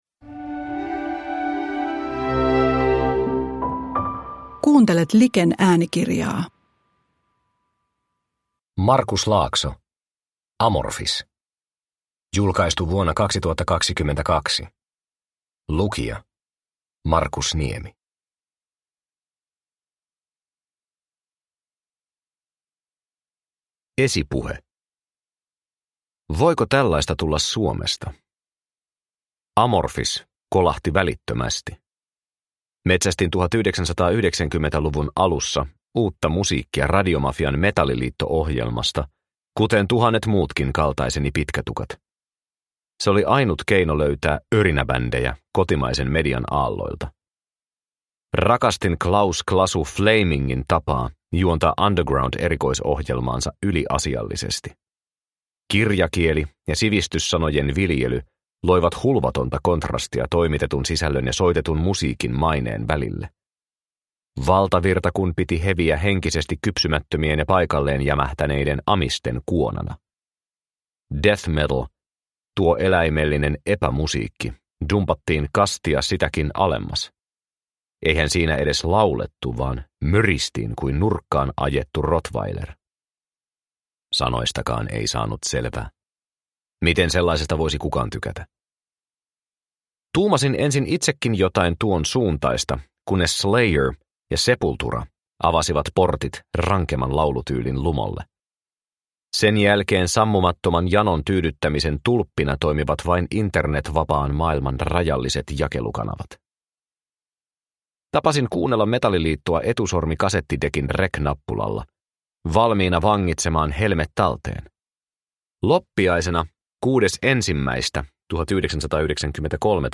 Amorphis – Ljudbok – Laddas ner